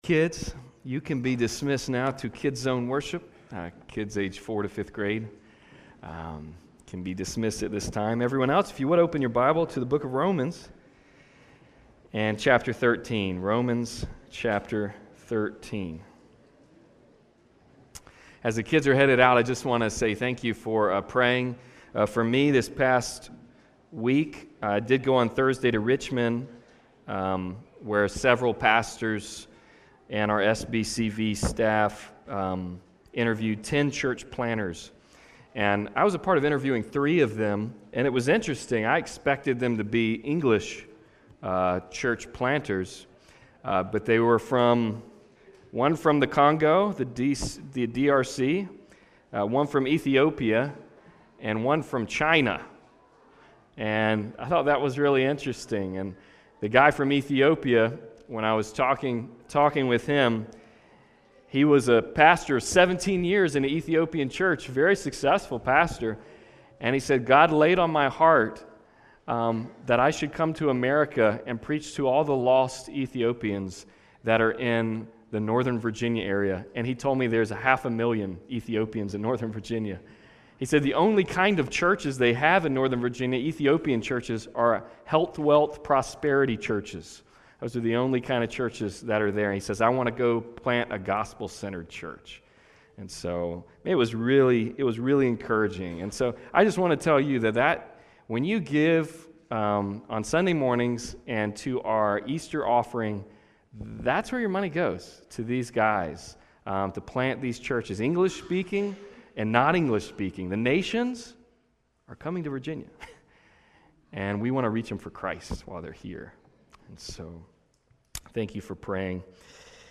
The Gospel Shaped Church Passage: Romans 13: 8-10 Service Type: Sunday Service « A Submitted Community